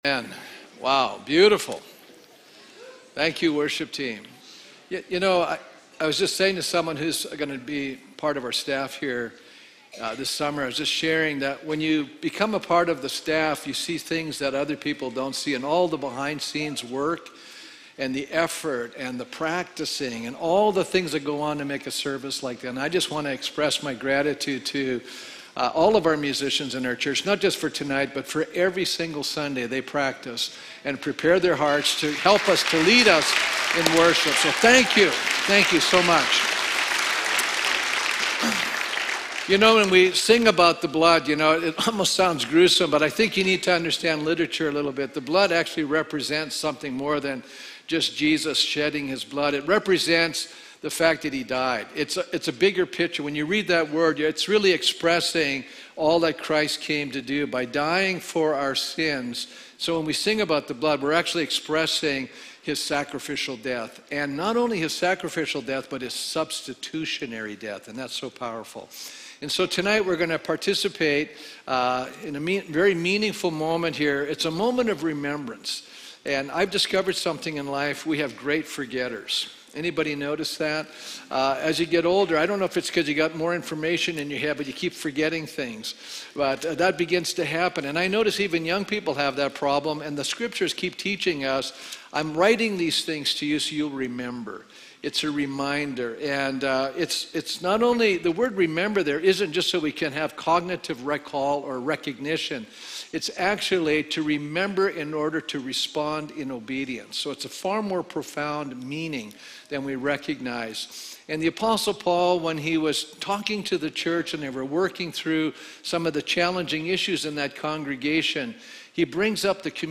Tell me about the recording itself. Good Friday Galatians 3:6-14 Living Stones Church, Red Deer, Alberta